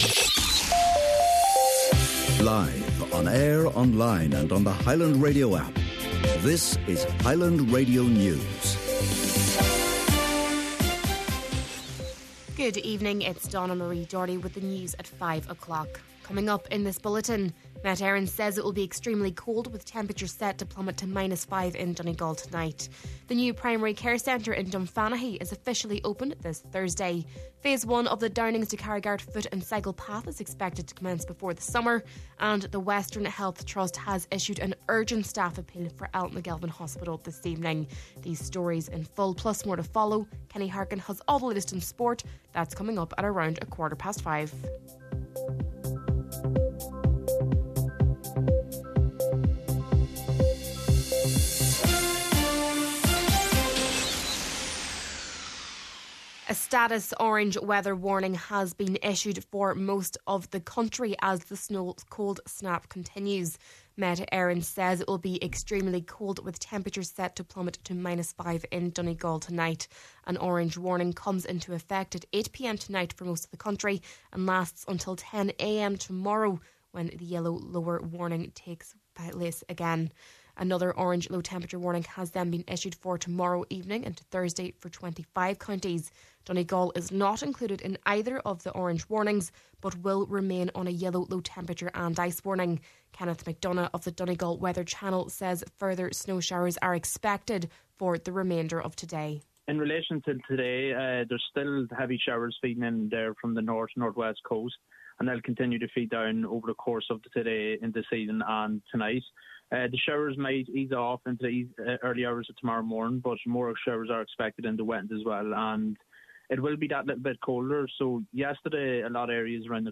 Main Evening News, Sport and Obituaries – Tuesday, January 7th